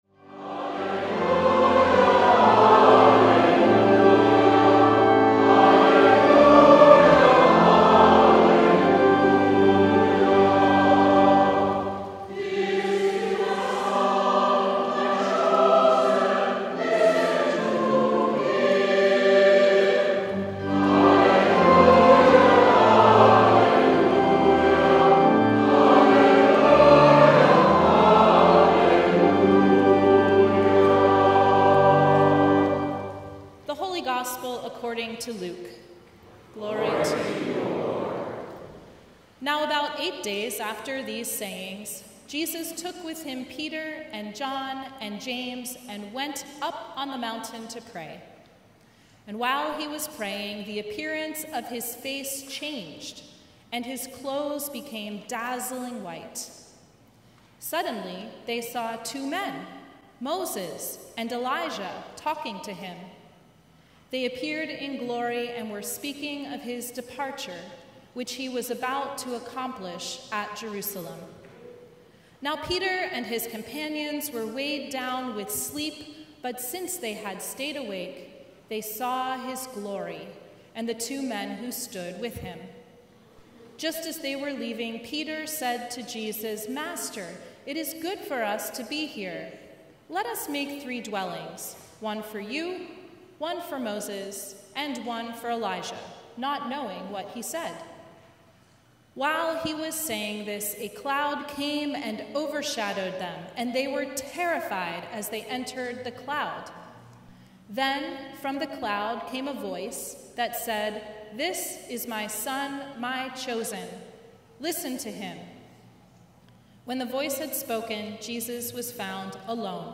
Sermon from Transfiguration | 2025 March 2, 2025